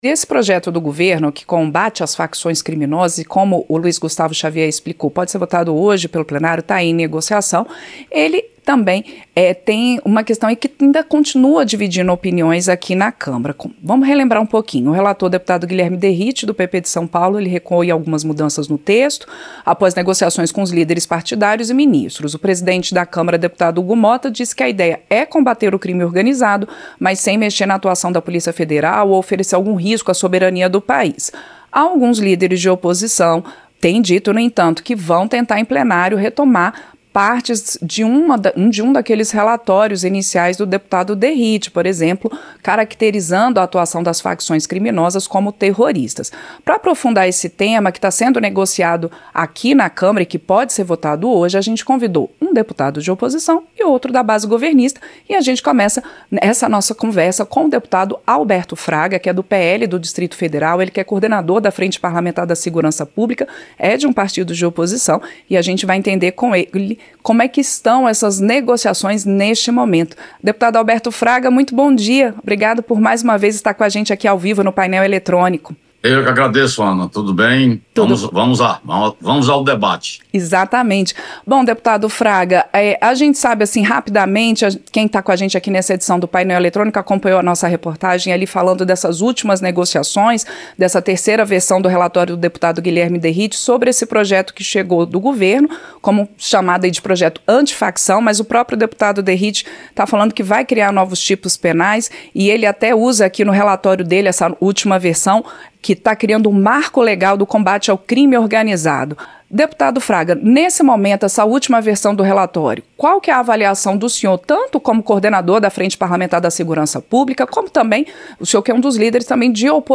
Entrevista - Dep.